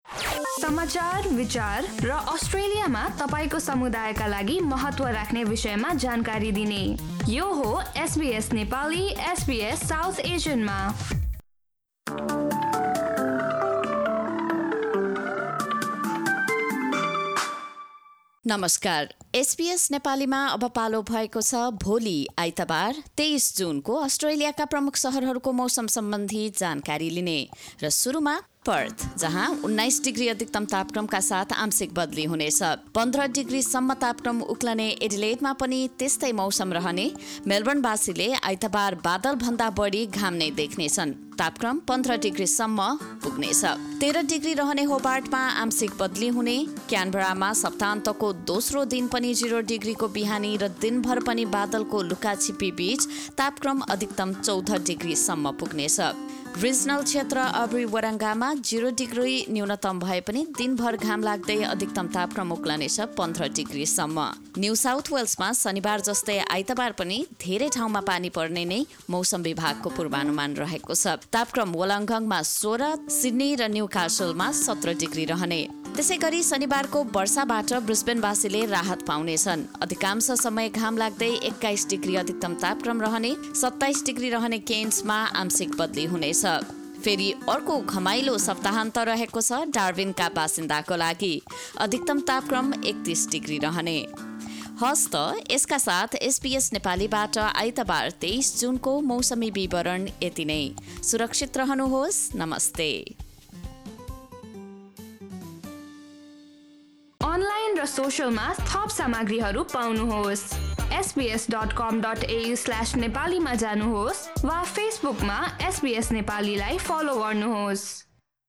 A quick Australian weather update in Nepali language.